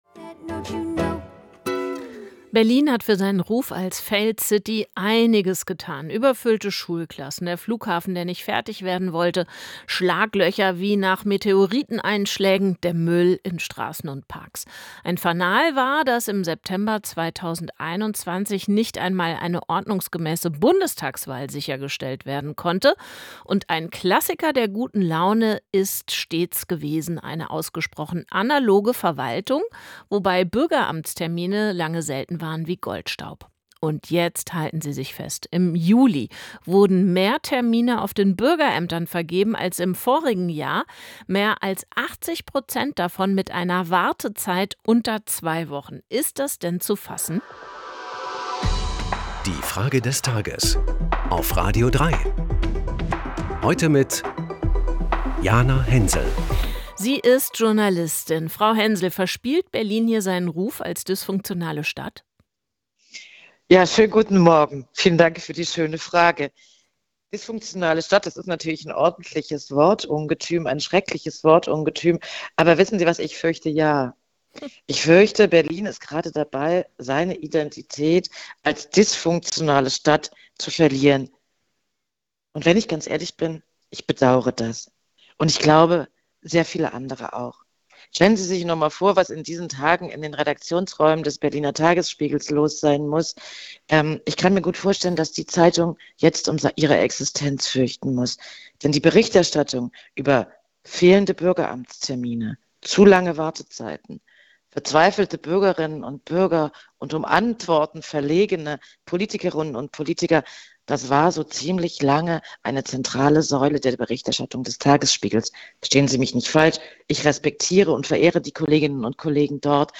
Es antwortet die Journalistin Jana Hensel.